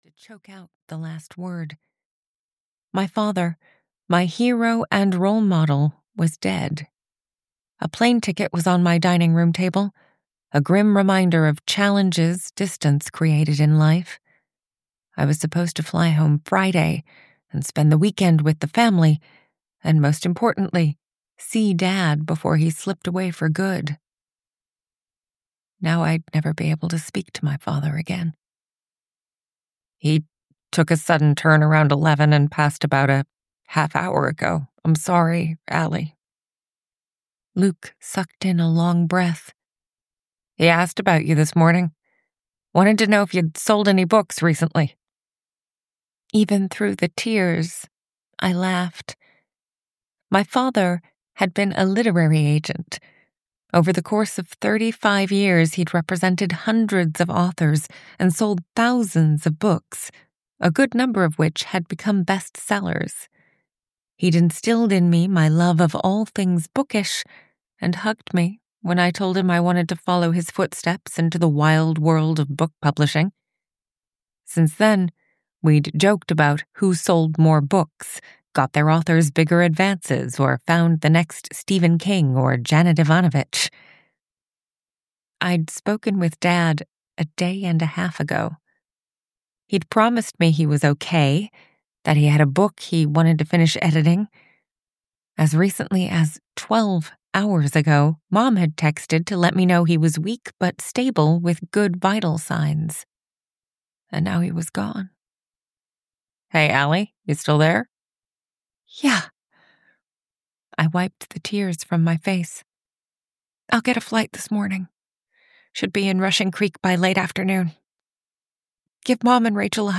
Audio knihaA Literal Mess (EN)
Ukázka z knihy